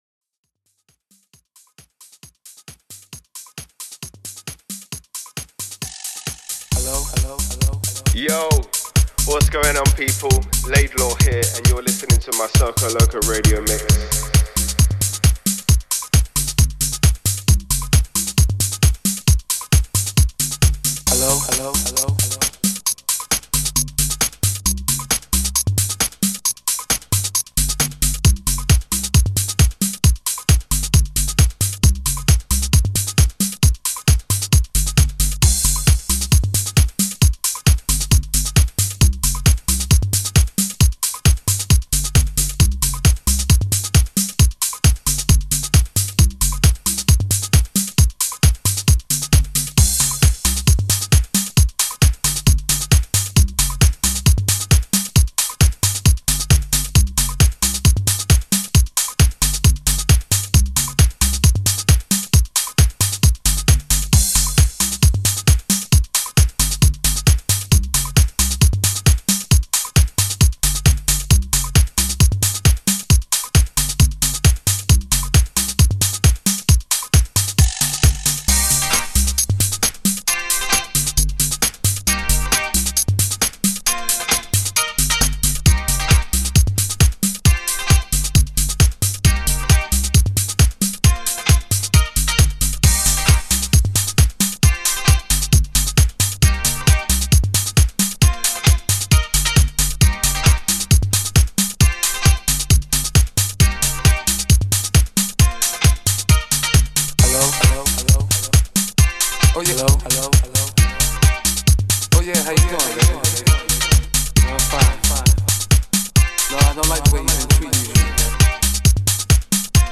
music DJ Mix in MP3 format
Genre: Tech House